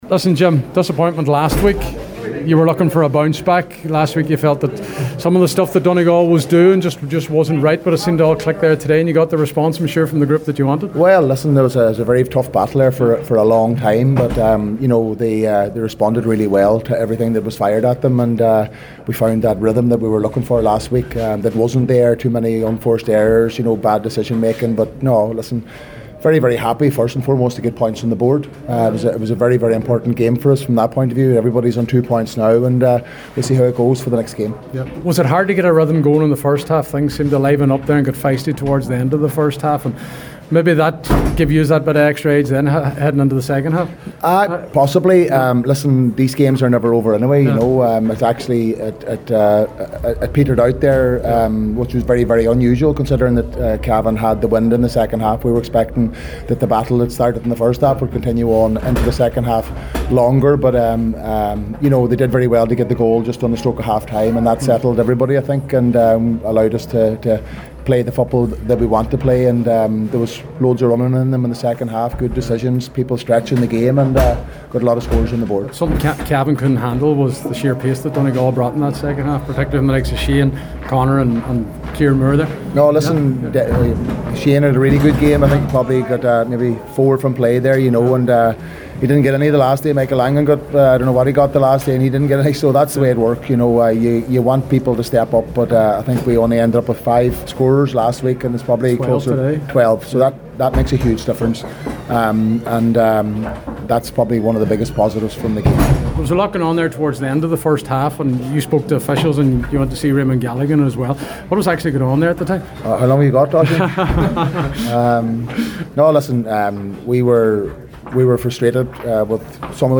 Donegal manager Jim McGuinness